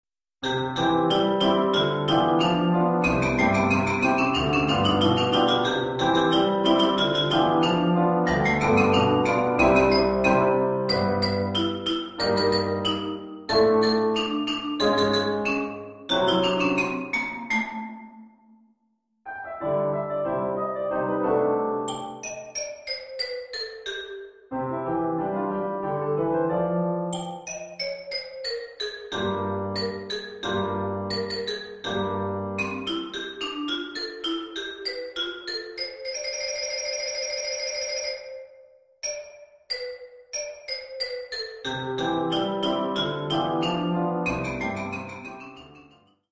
Oeuvre pour xylophone et piano.